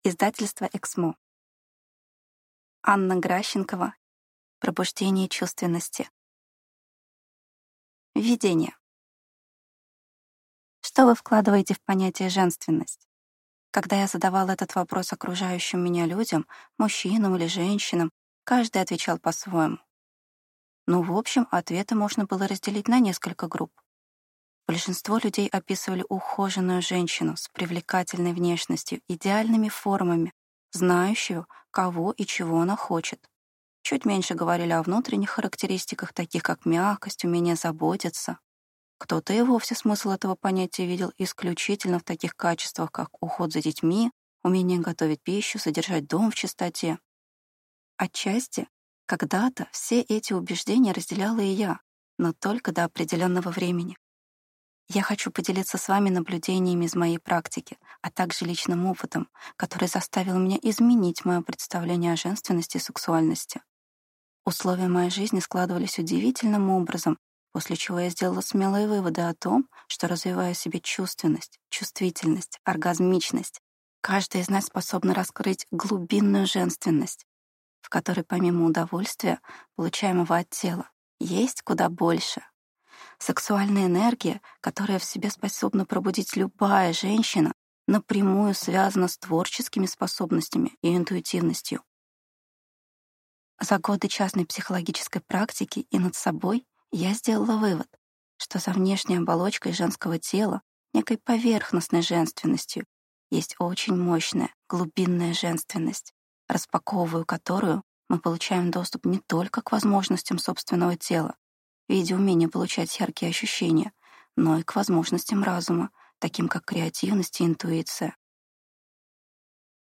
Аудиокнига Пробуждение чувственности. Как раскрыть свою сексуальность и научиться получать удовольствие | Библиотека аудиокниг